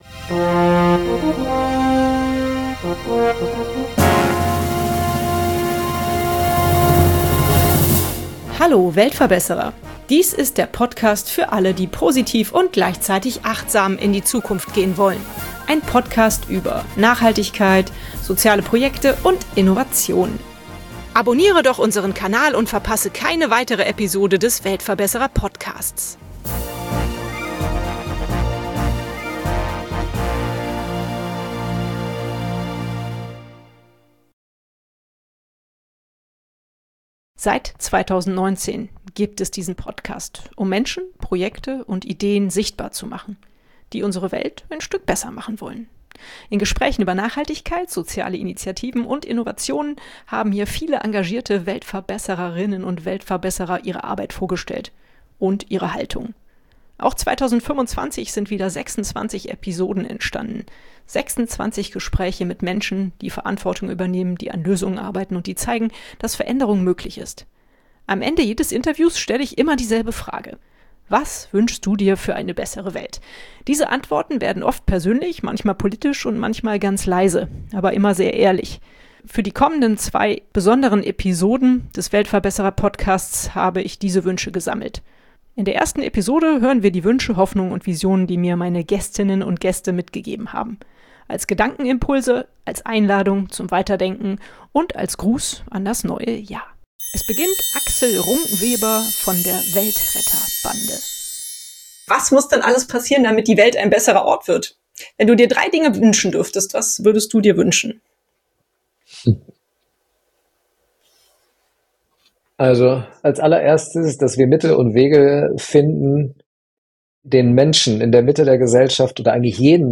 In dieser besonderen Episode habe ich diese Antworten gesammelt. Stimmen aus dem Podcastjahr 2025 – ehrlich, nachdenklich, hoffnungsvoll.
Diese Folge ist kein klassisches Interview, sondern eine Collage aus Gedanken, Hoffnungen und Visionen.